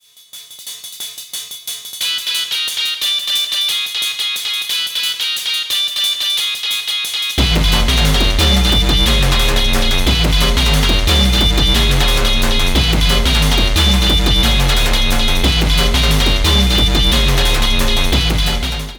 ALLOY IS ALIVE AND RAVING … and friends join in!
Alloy = Ride, China and Rave Stab